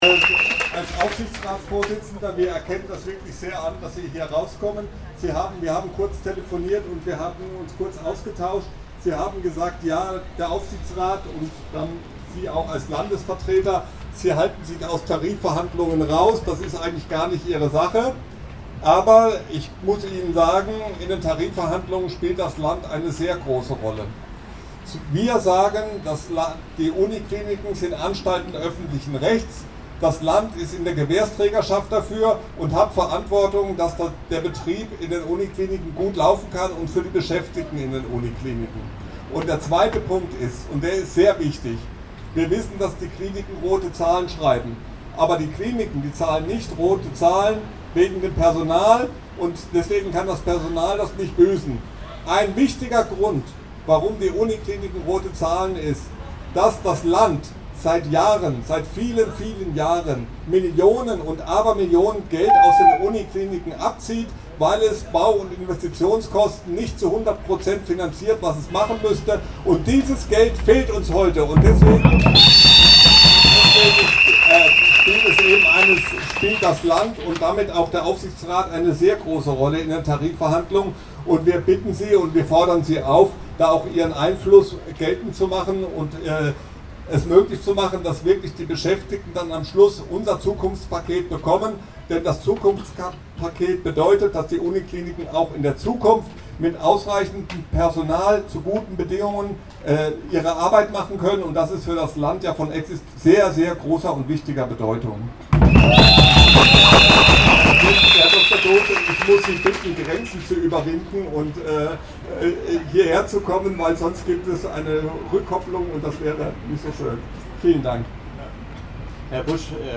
In Form einer Demonstration liefen die Gewerkschaftler*innen und die streikende Belegschaft aus der Kilianstraße vor das Hauptgebäude der Veraltung des Uni-Klinikums, in die Breisacher Str. 153.